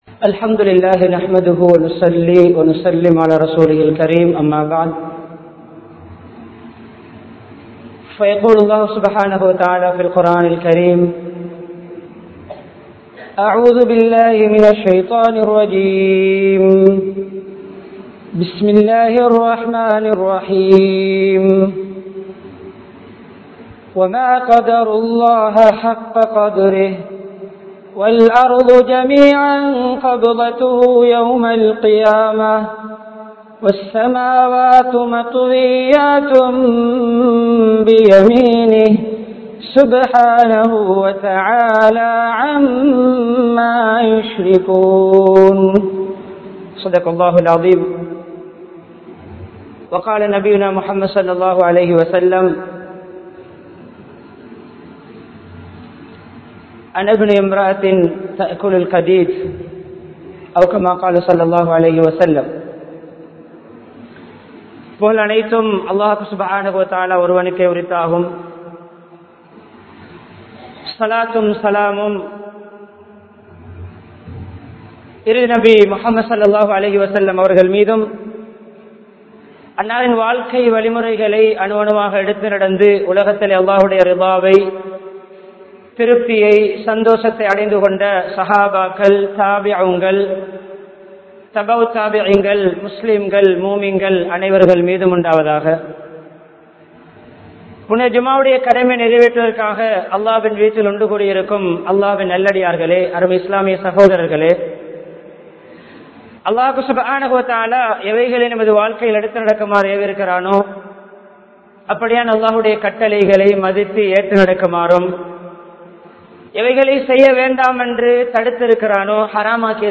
Akbar Town Jumua Masjidh